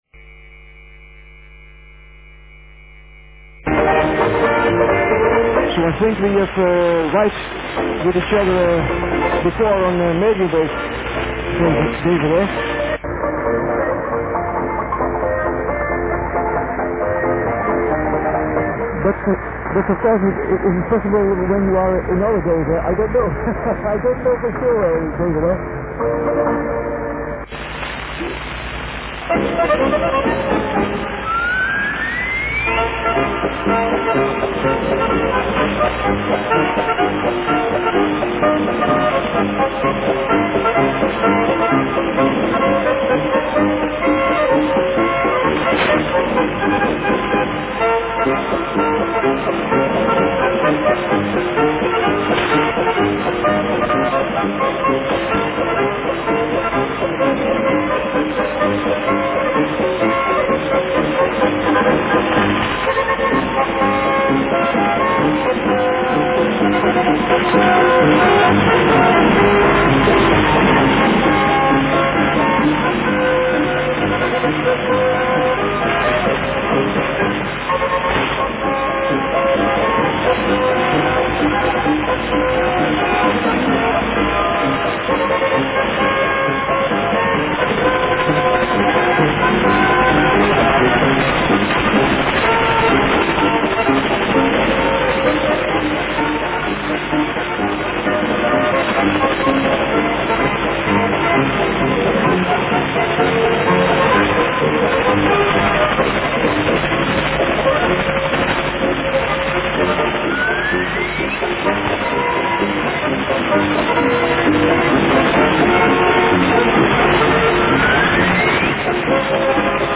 Hier und heute auch wieder einmal ein paar Mitschnitte einer holl. MW-Station.